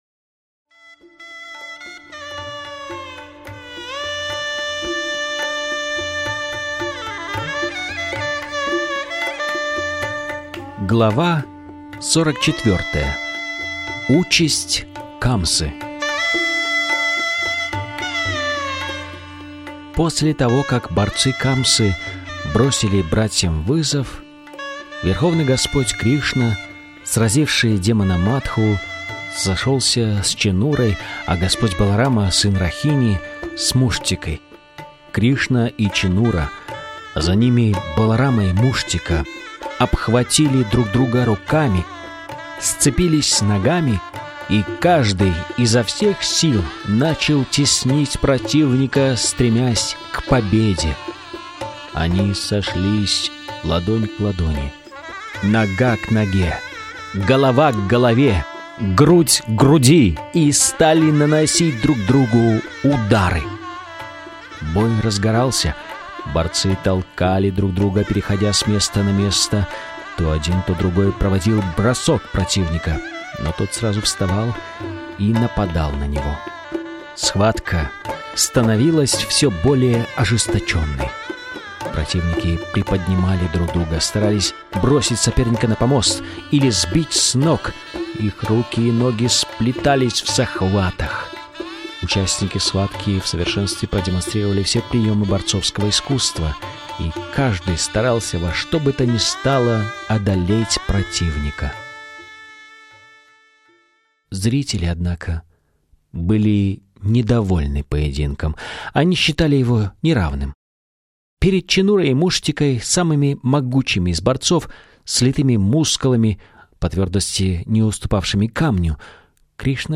Источник вечного наслаждения автор Абхай Чаран Де, Бхактиведанта Свами Прабхупада Информация о треке Автор аудиокниги : Абхай Чаран Де Бхактиведанта Свами Прабхупада Аудиокнига : Кришна.